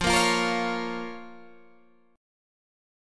Fsus4#5 chord